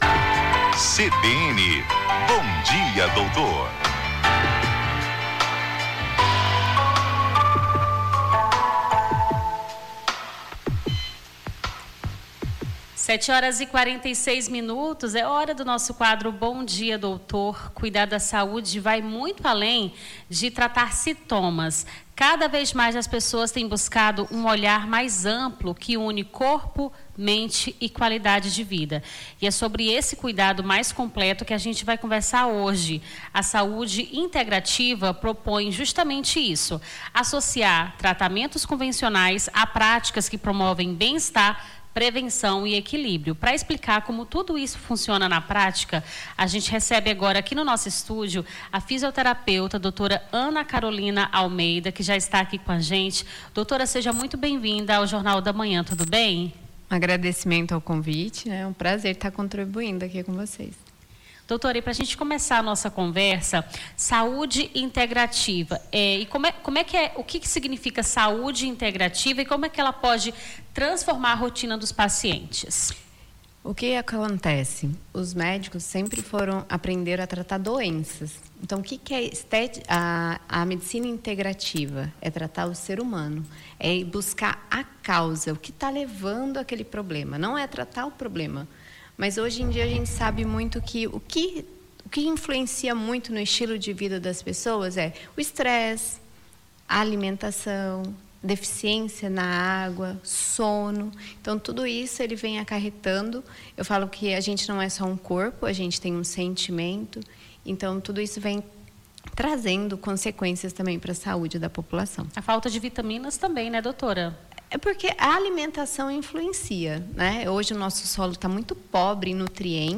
Bom Dia Doutor: fisioterapeuta esclarece dúvidas sobre saúde integrativa